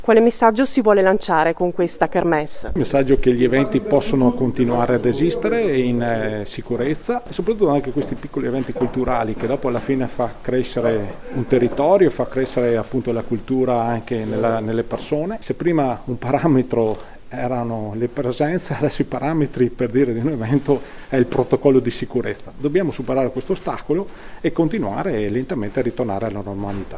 Ne hanno parlato al microfono della nostra corrispondente